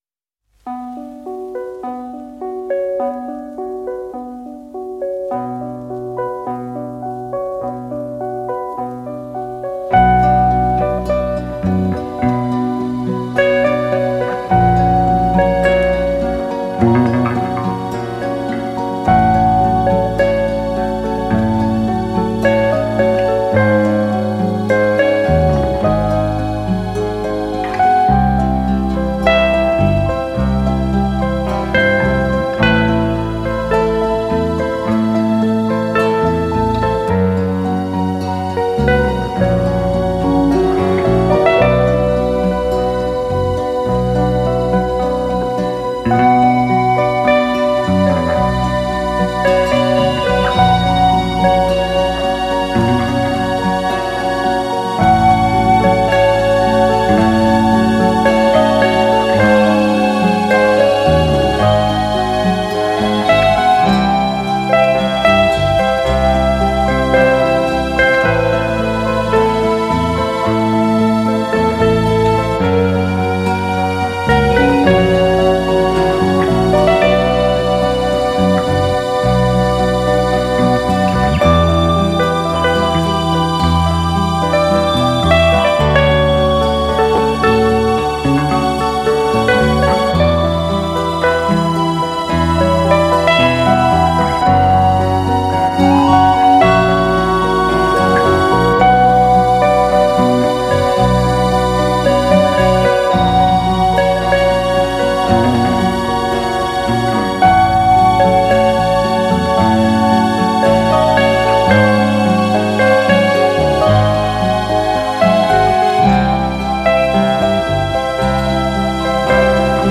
シンセとギターが心地良い、なんともしなやかなコズミック・ディスコを展開っしています！